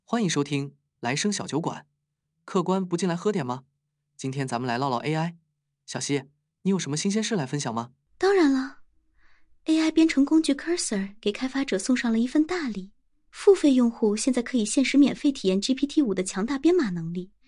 Podcast-Generator - 播客音频生成cli ，python服务端 和 web前端，支持单人和多人对话。支持原文智能配音。
minimax.wav